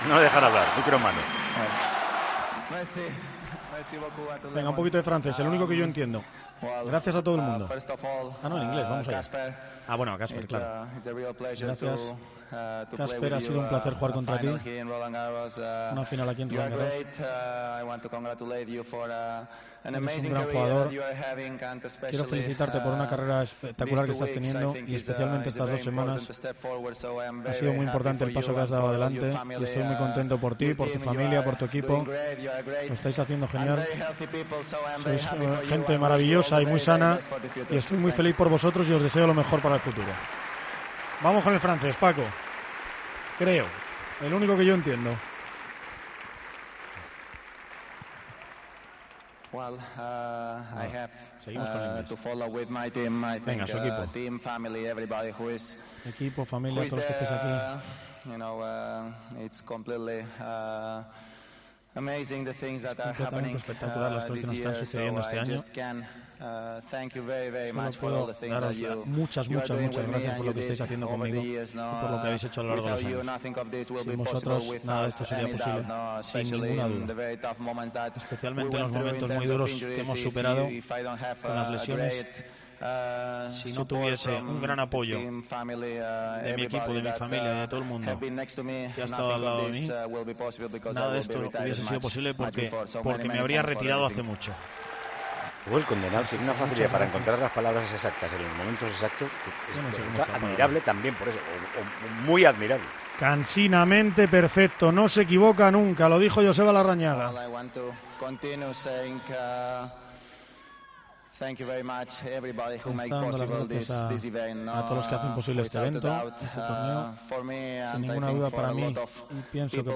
Las primeras palabras de Nadal tras levantar su 14º Roland Garros: "Es muy difícil describir los sentimientos que tengo".
"No sé lo que me reserva el futuro, pero voy a luchar por volver el máximo de años posible", dijo el español desde la pista central de París, con la Copa de los Mosqueteros en las manos, su vigésimo segundo Grand Slam, el segundo que gana esta temporada.